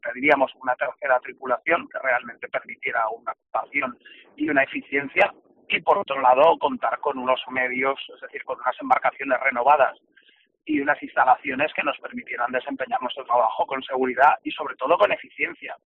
En Herrera en COPE Canarias hemos hablado con un representante sindical de Salvamento Marítimo, quien ha pedido mayores recursos para su entidad.